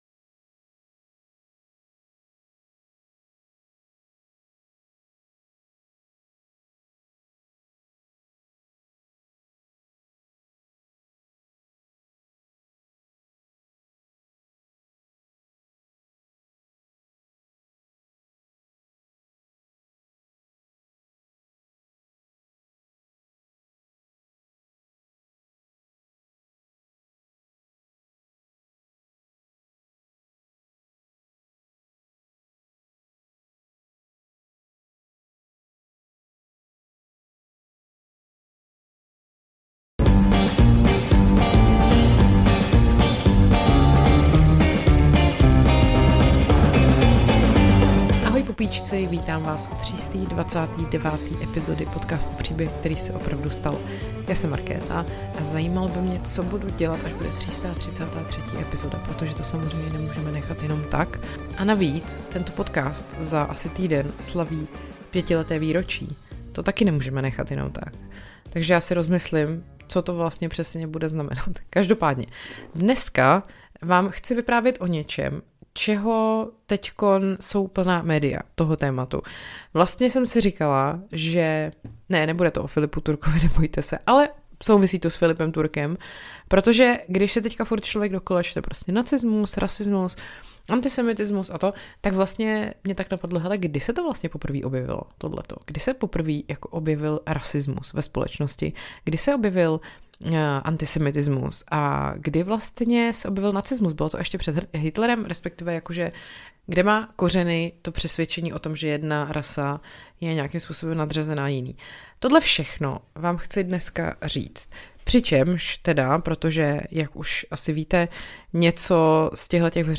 Audio referáty o tom, co jste ani netušili, že vás bude bavit.